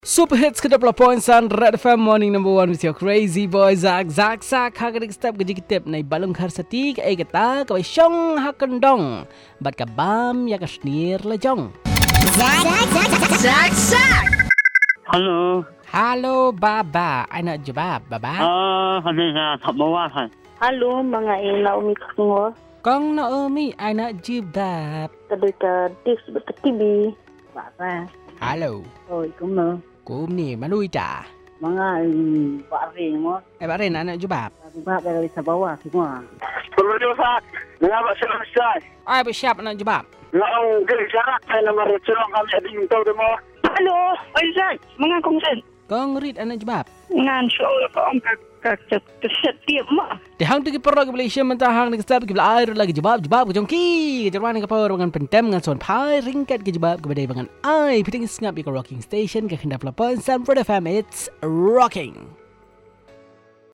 Calls